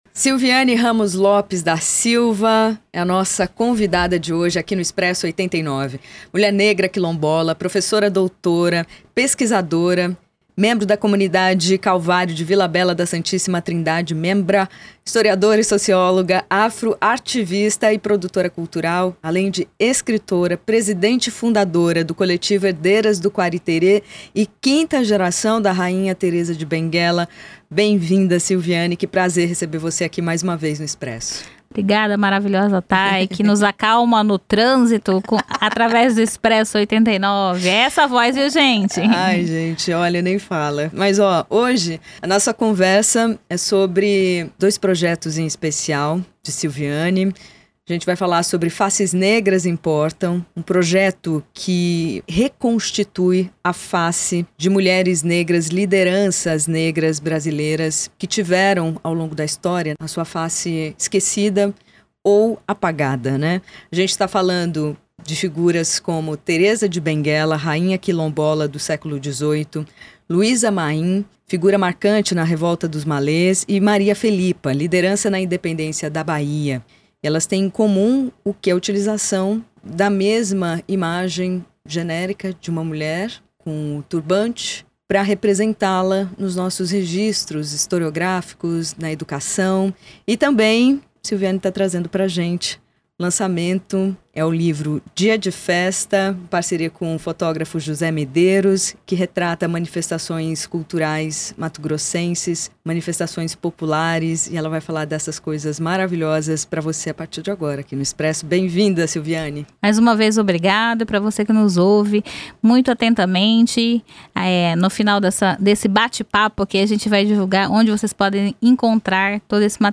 Expresso 89 Entrevista